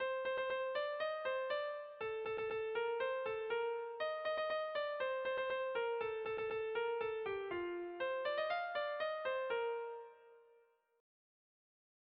8A / 8A / 10 / 8A / 8A (hg) | 8A / 8A / 18A / 8A (ip)
A-B-C-D